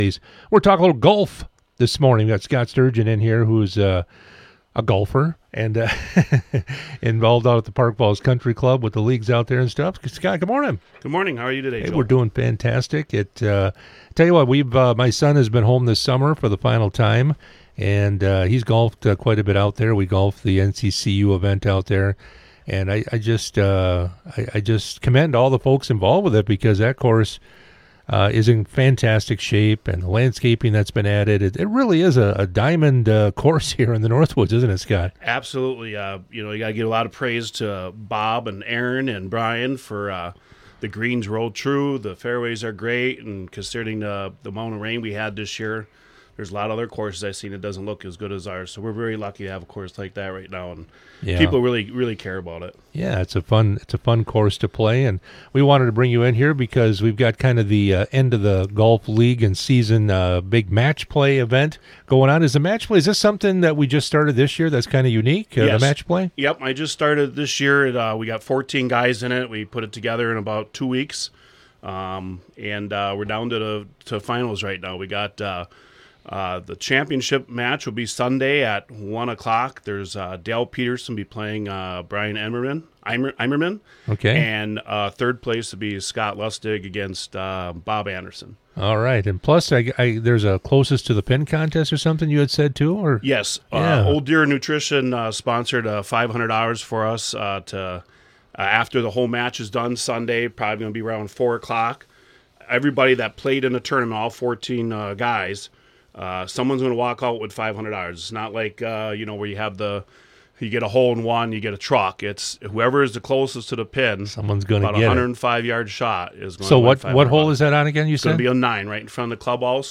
Interviews and special broadcasts from 98Q Country in Park Falls.
98q interviews